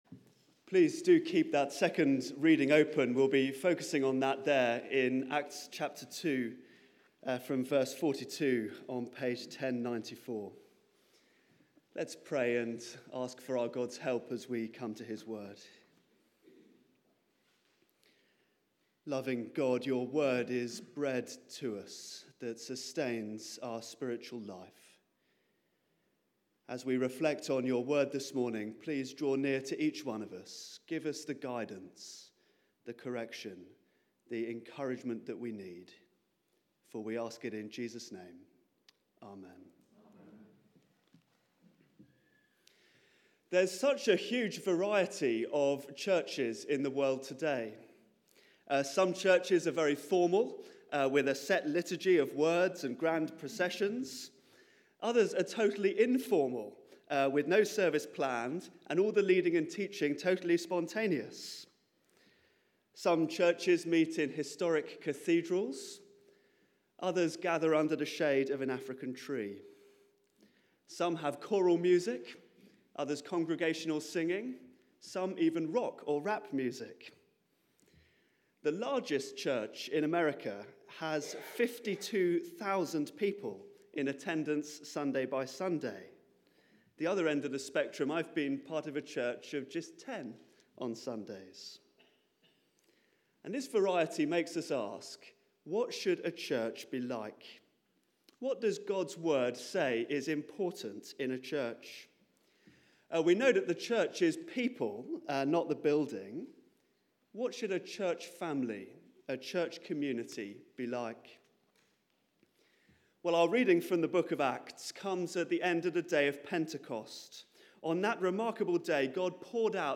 Media for 9:15am Service on Sun 08th Jan 2017 09:15 Speaker
Theme: The Spirit-Filled Church Sermon